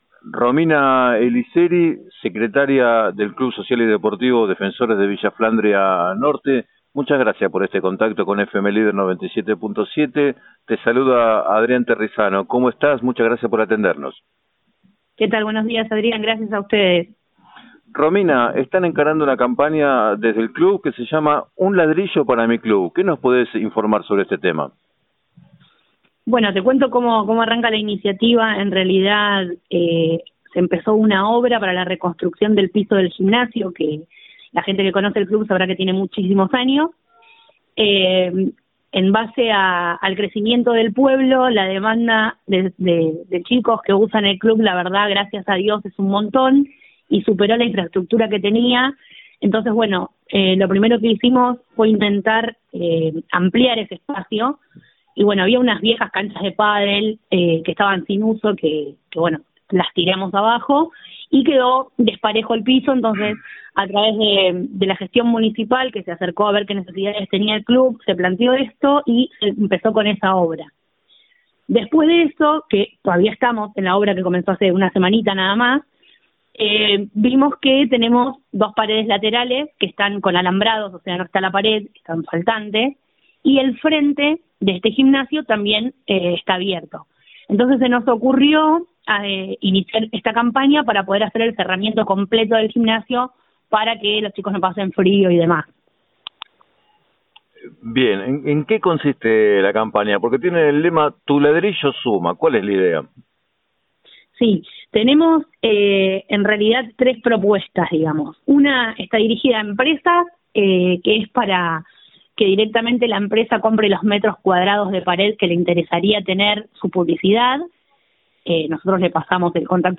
En declaraciones al programa 7 a 9 de FM Líder 97.7